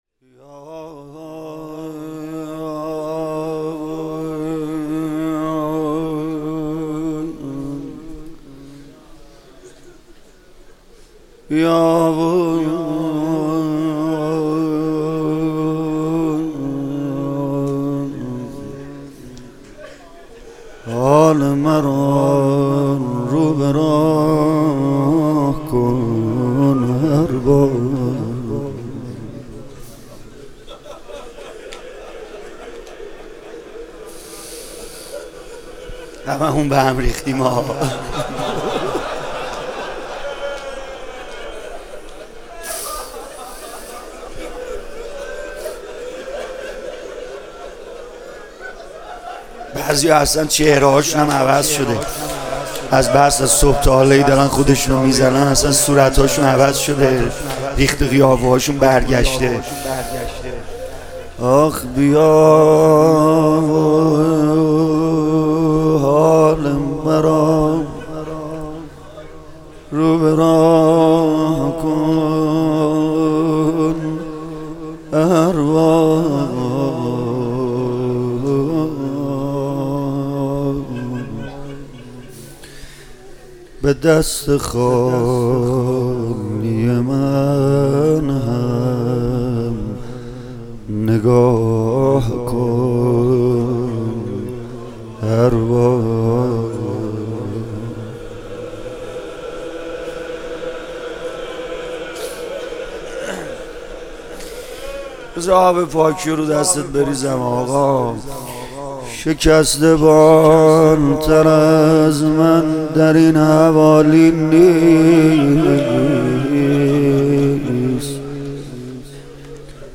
محرم1401 - شب یازدهم محرم - مناجات و روضه